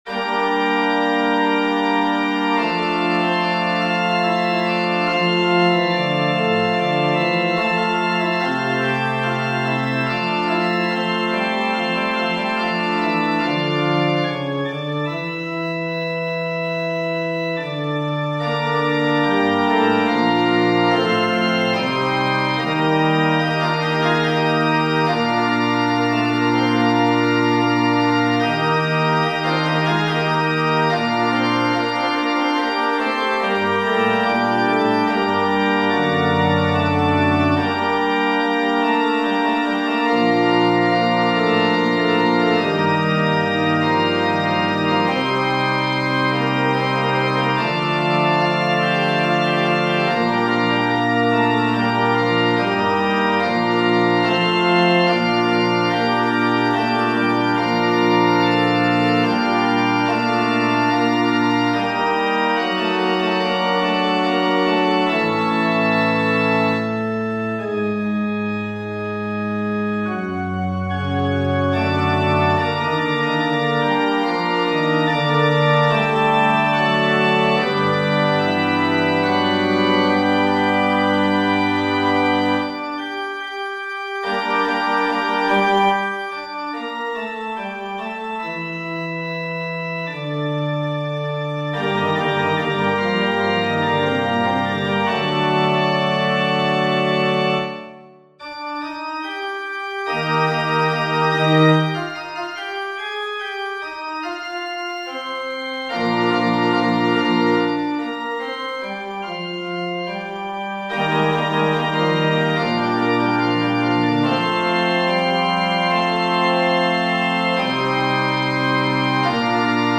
FF:VH_15b Collegium musicum - mužský sbor, FF:HV_15b Collegium musicum - mužský sbor